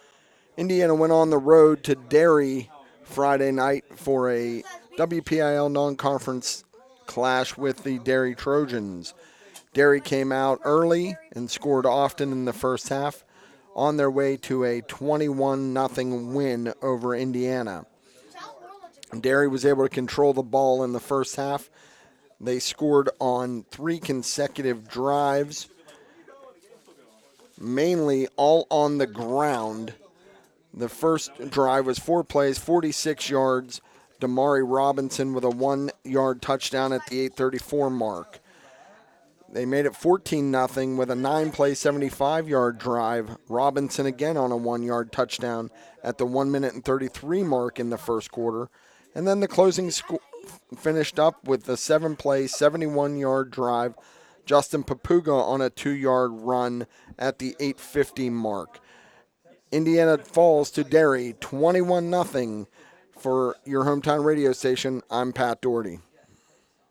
hsfb-indiana-vs-derry-recap.wav